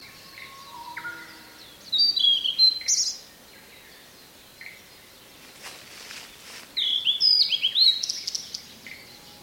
鳴き声
2013年7月8日 コジュケイ（東京都八丈島 早朝）